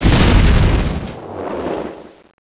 Old handhelds file archive / Pocket Pc Software / Games / StarCraft v2.2.2 / Data / sounds / terran / units / battlecruiser / death File Name Size Date ..